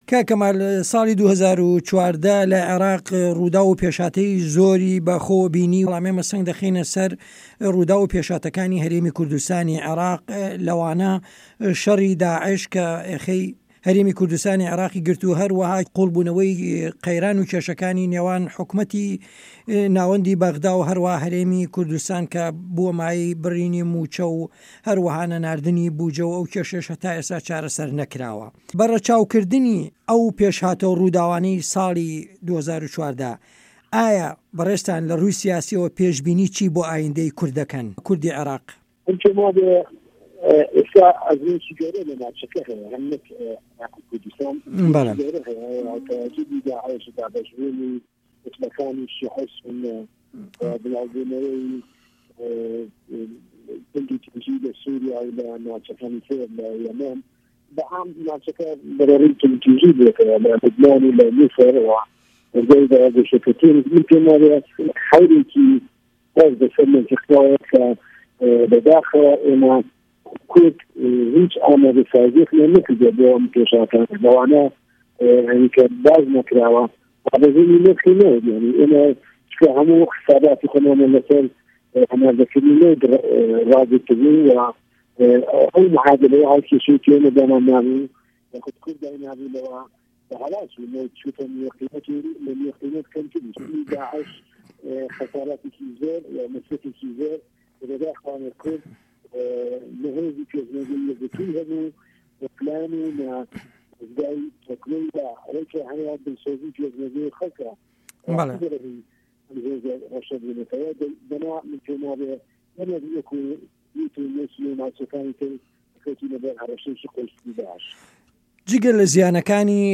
عێراق - گفتوگۆکان